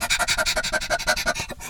dog_2_breathig_02.wav